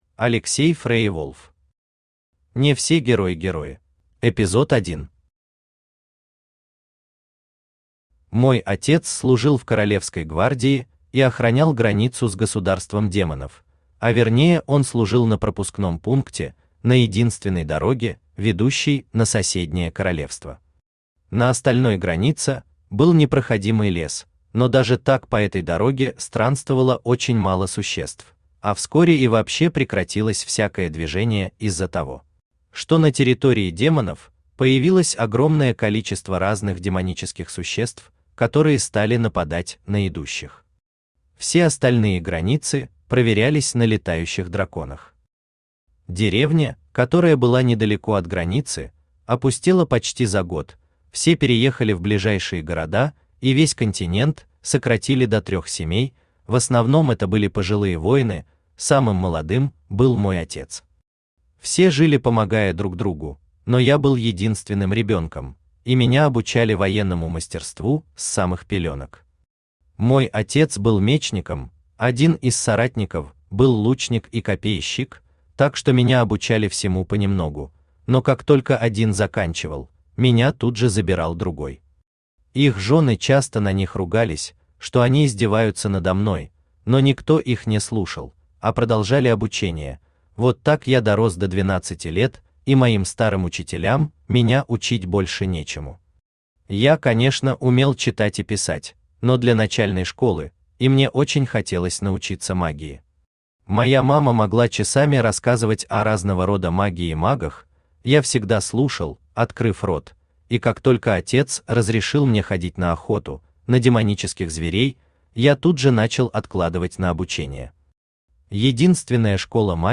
Аудиокнига Не все герои-герои | Библиотека аудиокниг
Aудиокнига Не все герои-герои Автор Алексей Леонидович FreierWolf Читает аудиокнигу Авточтец ЛитРес.